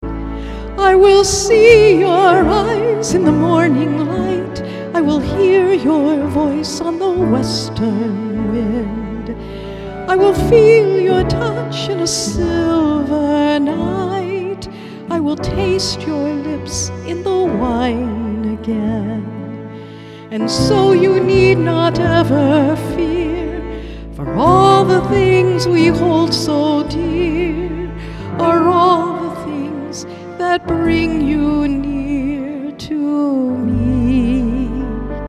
“soul-pop